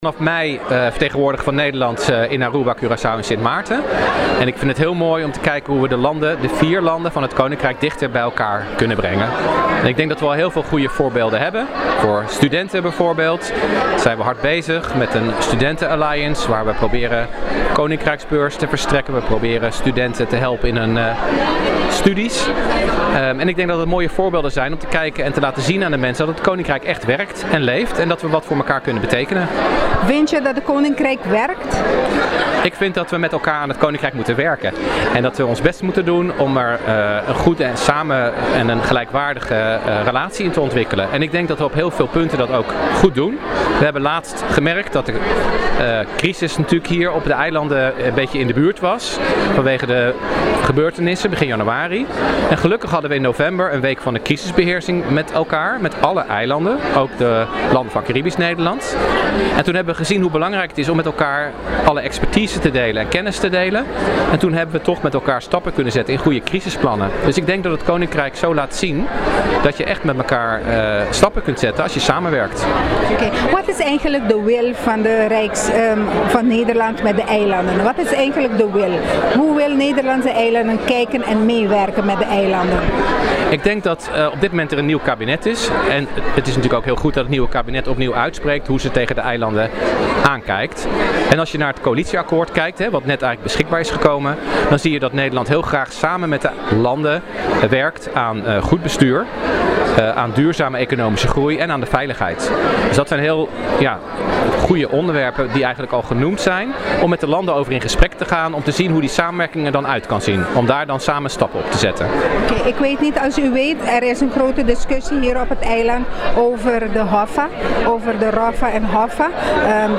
During the activity, our newsroom had the opportunity to speak with Representative Gerbert Kunst about several current issues dominating public discussion in Aruba.
He was approachable for the press and responded in a cordial manner to questions concerning issues and concerns present within the Aruban community topics that people are not always fully aware of.